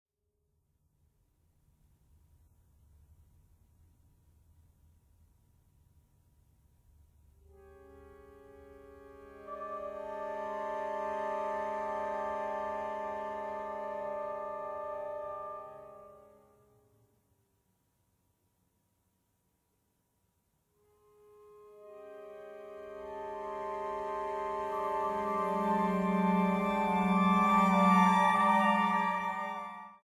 Música de Cámara